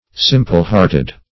Meaning of simple-hearted. simple-hearted synonyms, pronunciation, spelling and more from Free Dictionary.
Search Result for " simple-hearted" : The Collaborative International Dictionary of English v.0.48: Simple-hearted \Sim"ple-heart`ed\, a. Sincere; inguenuous; guileless.